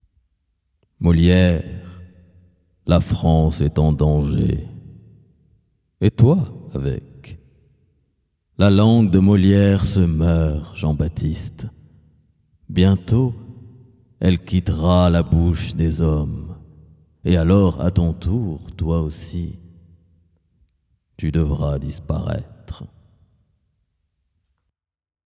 Extrait Voix OFF 1
28 - 45 ans - Baryton-basse Baryton